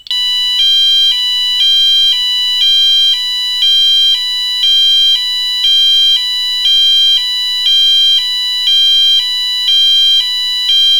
Notes: Makes 8 different tones: Horn, Code-3 Horn, March-Time Horn, Code-3 Tone, Hi/Lo, Slow Whoop, Siren, and Bell (vibrating).